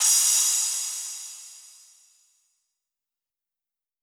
edm-crash-01.wav